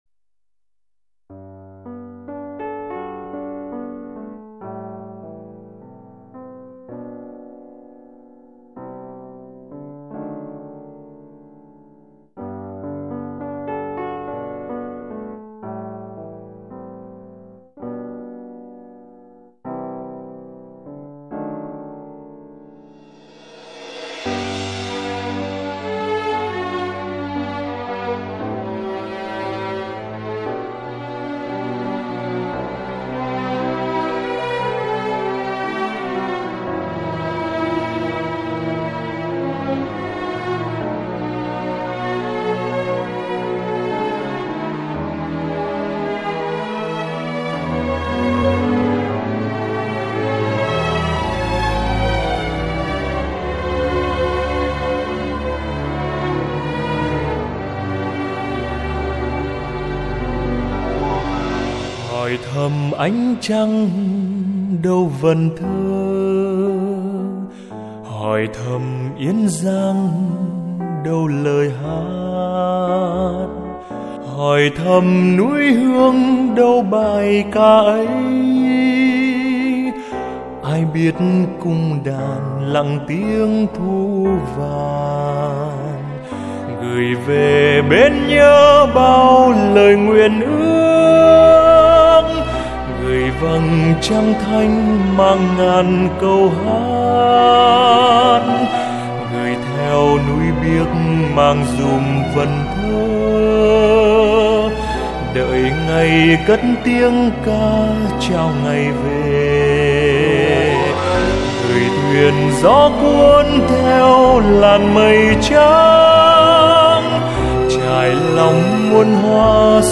Nhạc Phật